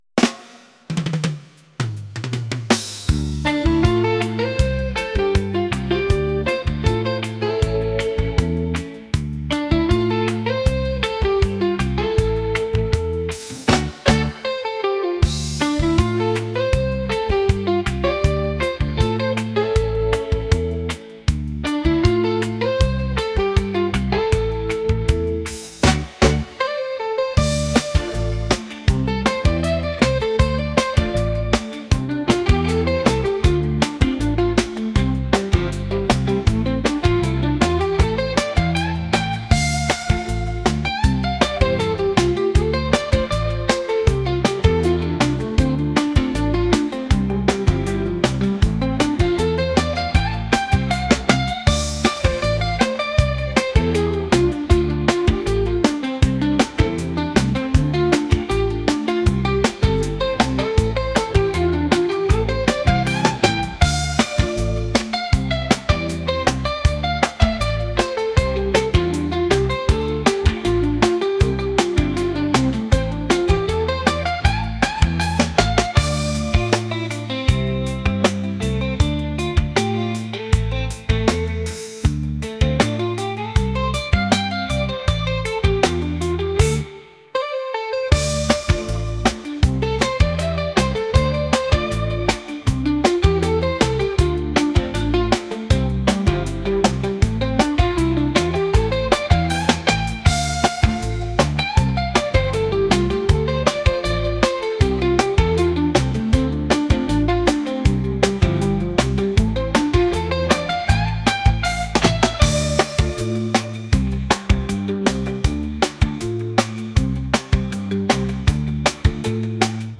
reggae | rock